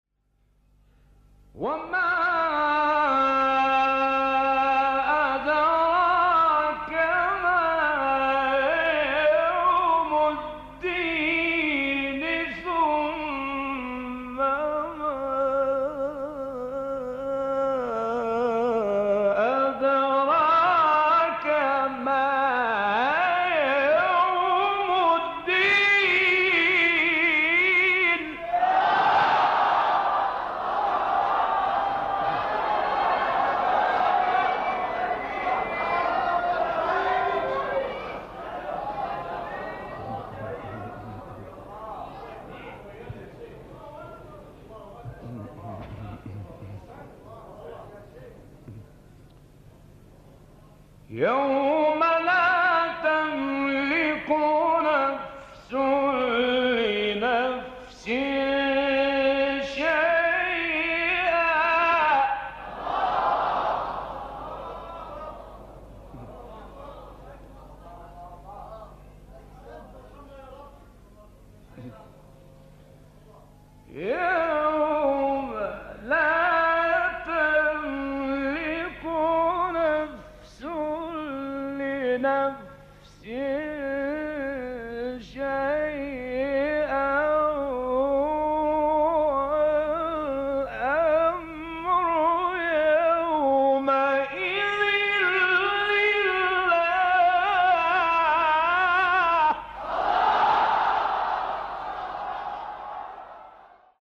قطعات شنیدنی از تلاوت سوره مبارکه انفطار را با صوت قاریان محمد اللیثی، شحات محمد انور، عبدالباسط محمد عبدالصمد، محمد صدیق منشاوی و راغب مصطفی غلوش می‌شنوید.
آیات ۱۷ تا ۱۹ سوره انفطار با صوت راغب مصطفی غلوش